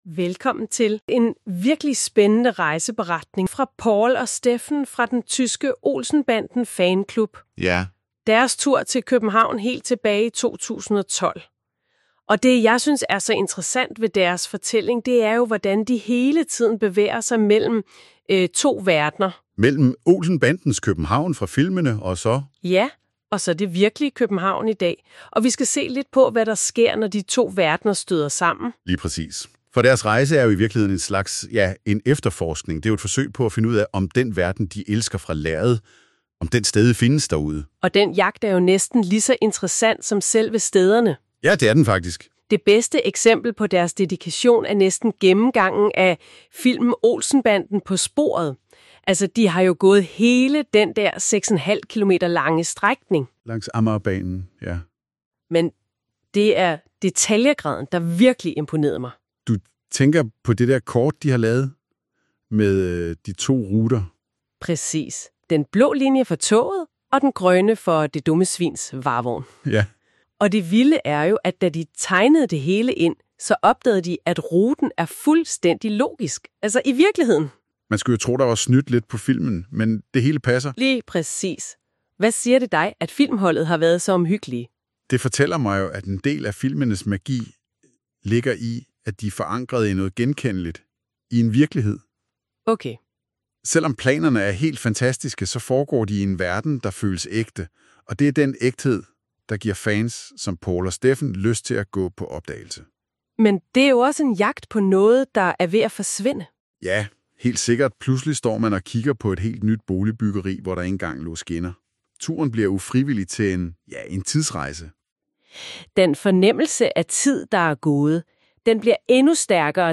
Lydresumé i podcastformat
MP3 (AI-genereret lydindhold)